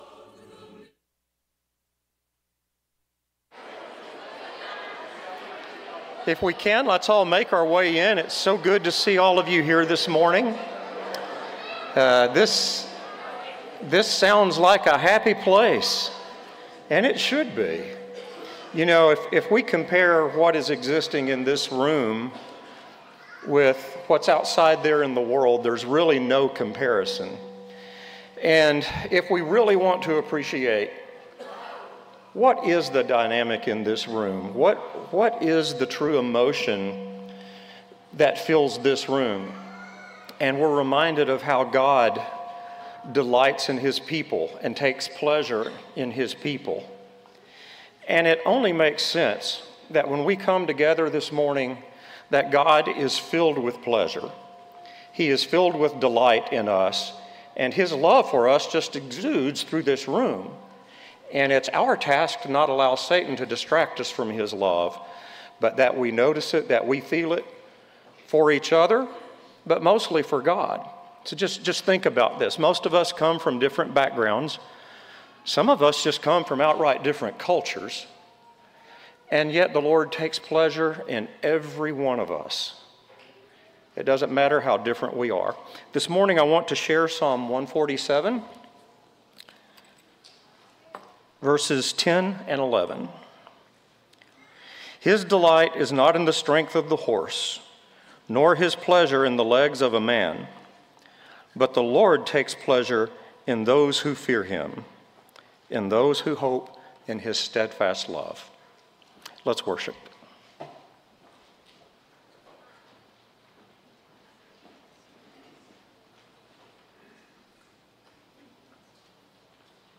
John 1:11, English Standard Version Series: Sunday AM Service